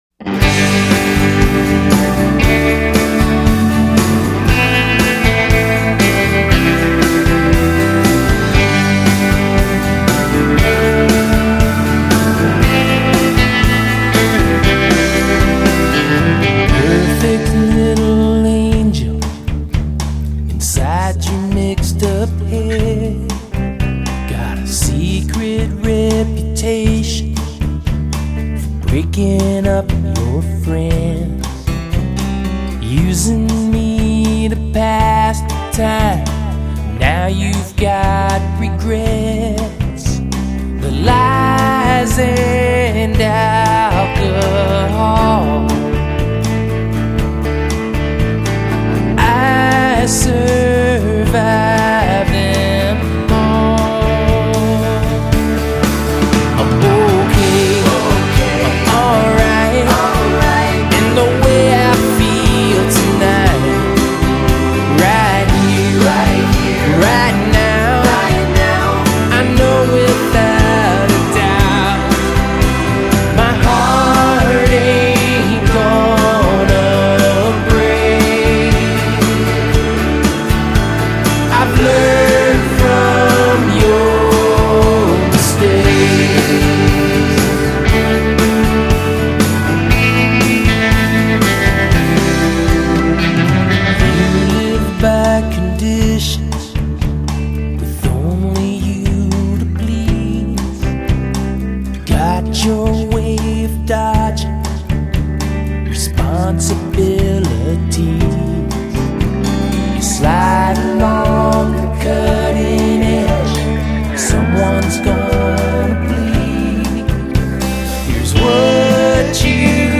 Lead vocal, acoustic guitar
Backing vocals
Electric and acoustic guitars
Organ
Wurlitzer piano
Bass
Drums
Percussion
Pedal steel